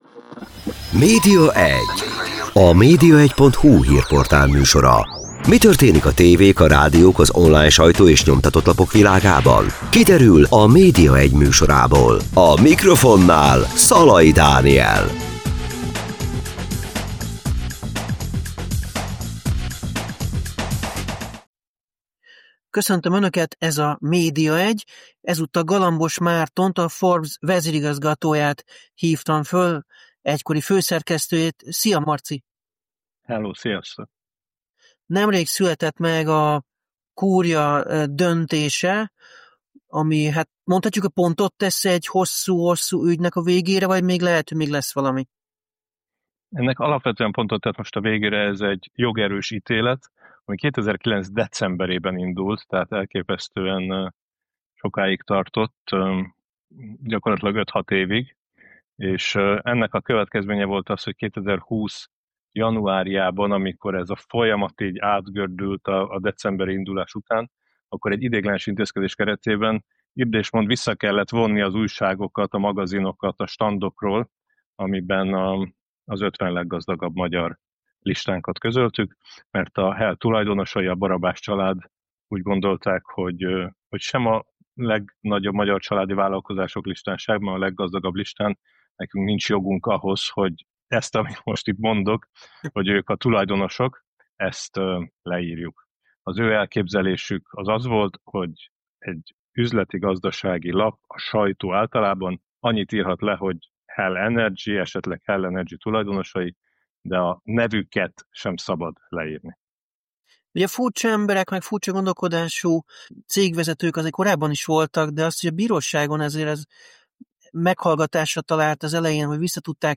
Interjú a bírósági ügy fejleményeiről és tanulságairól.